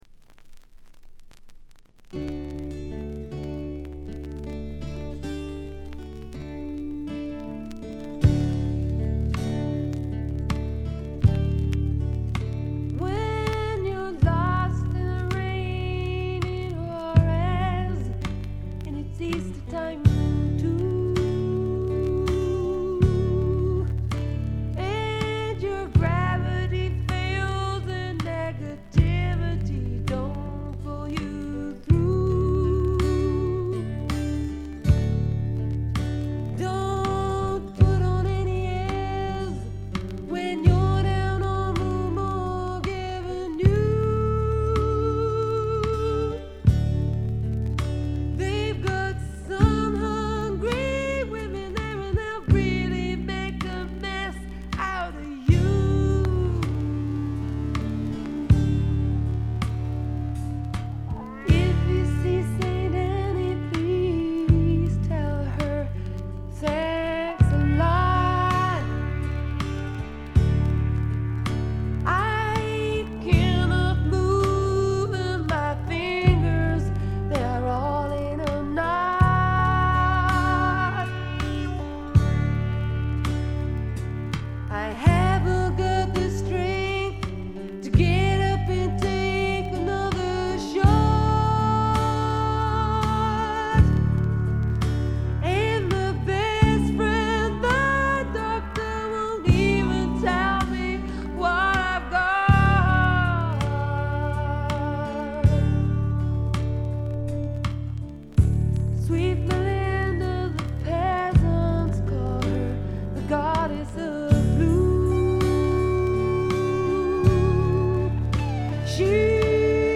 バックグラウンドノイズが常時出ており静音部ではやや目立ちます。
英国の女性シンガー・ソングライター／フォークシンガー。
試聴曲は現品からの取り込み音源です。